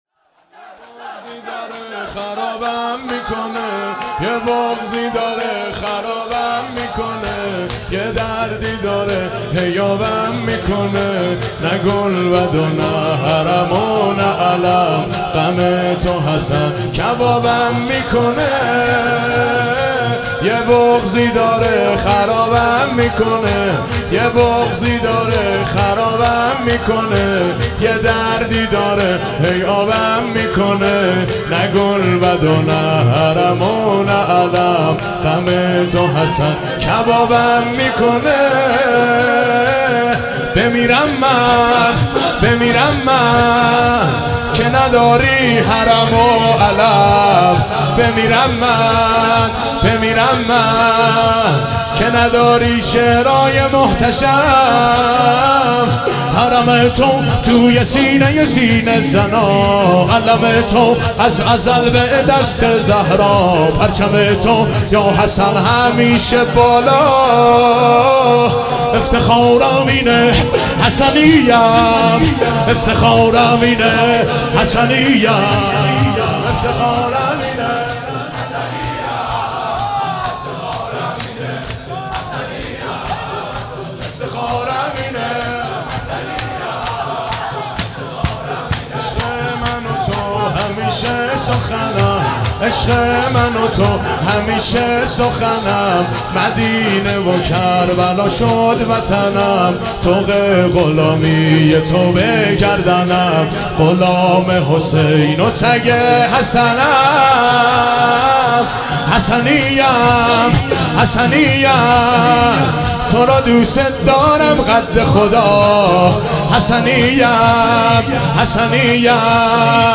احساسی